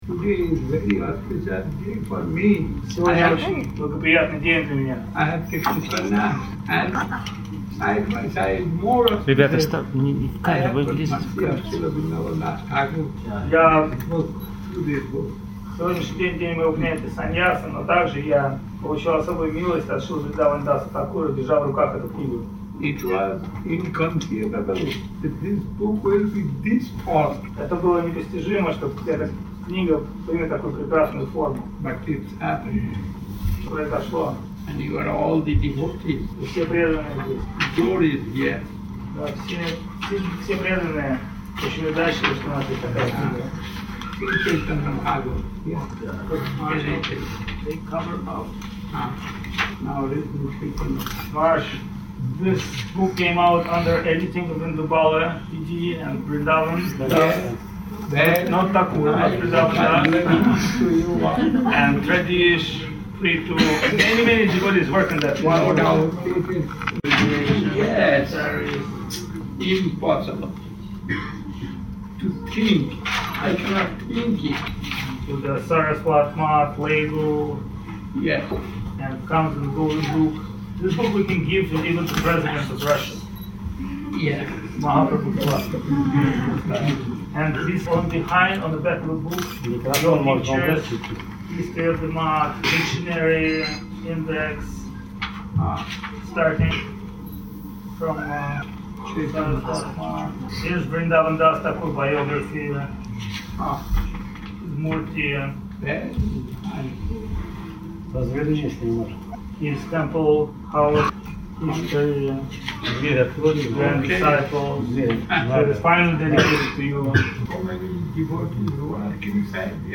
Киртан, песня прославляющая Господа Нитьянанду.
Place: Sri Chaitanya Saraswat Math Saint-Petersburg